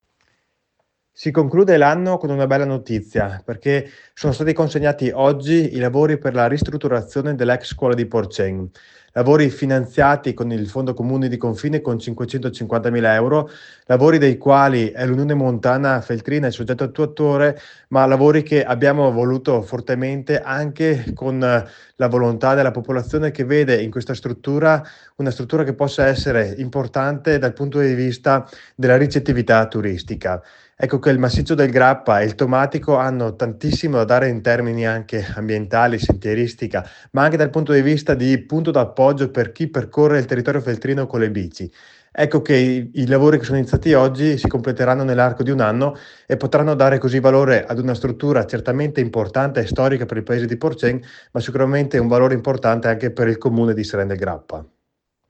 SEREN DEL GRAPPA AI MICROFONI DI RADIOPIU IL SINDACO DARIO SCOPEL